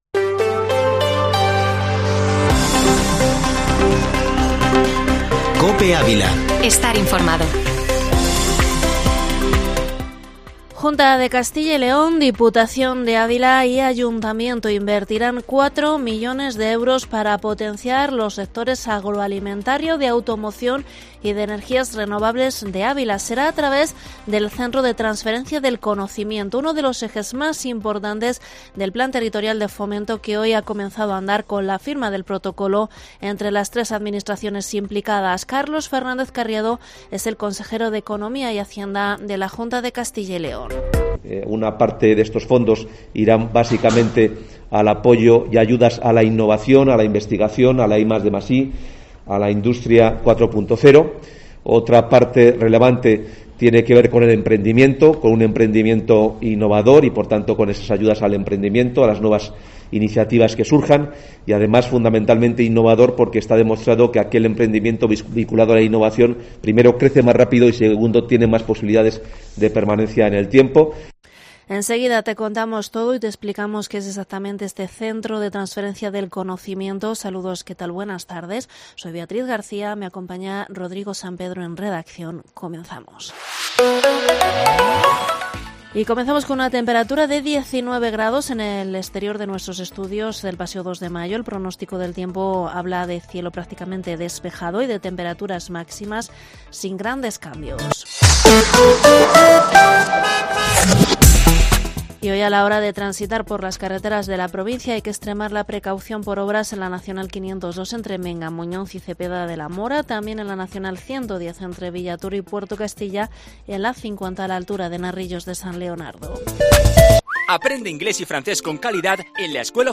informativo Mediodía COPE ÁVILA 08/10/2021